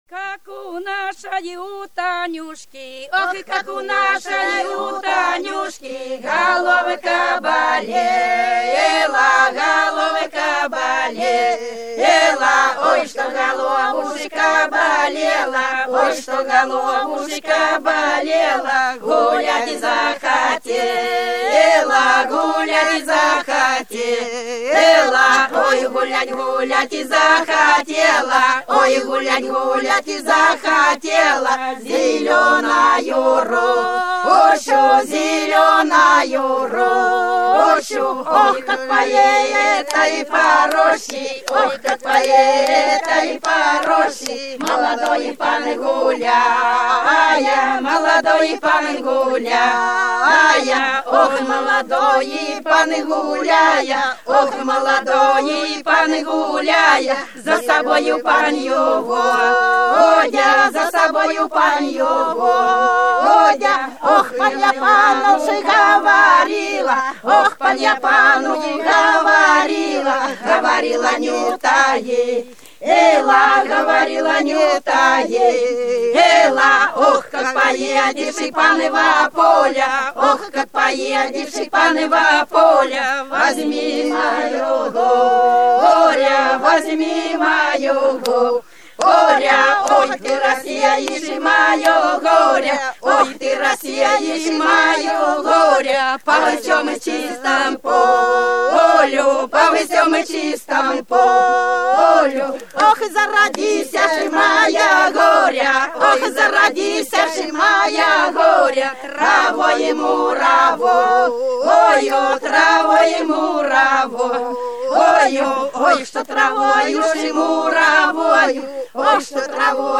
Вдоль по улице пройду (Бутырки Репьёвка) 024. Как у нашей у Дуняши — плясовая песня.